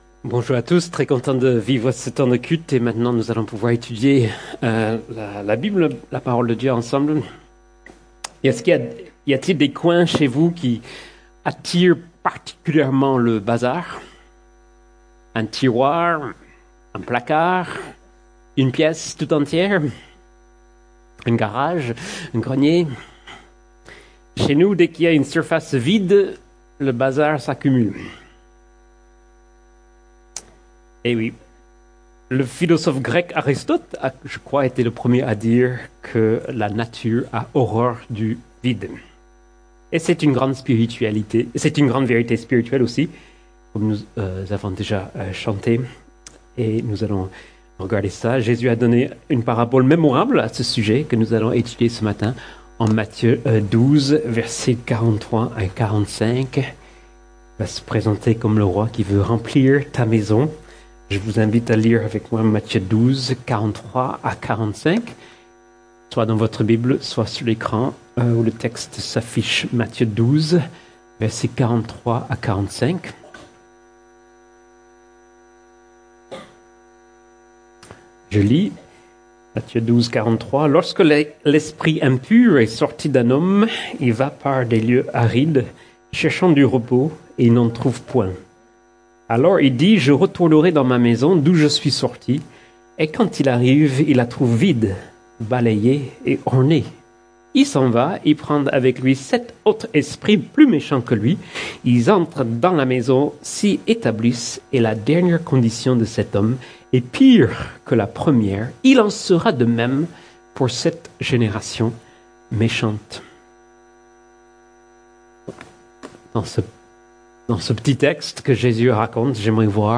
Sermons - Église Baptiste Toulouse Métropole